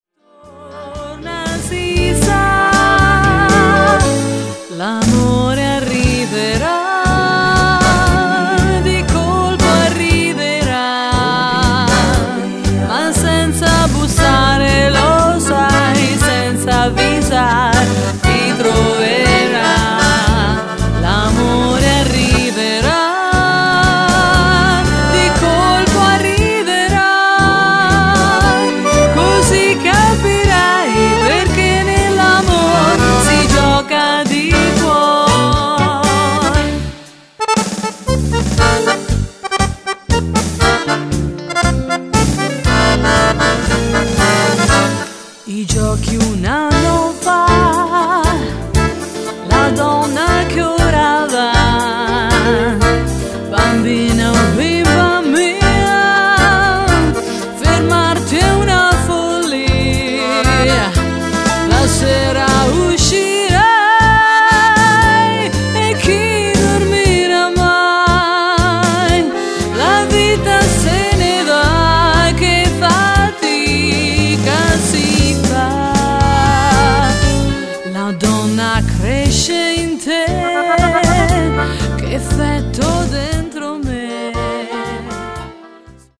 Tango
Donna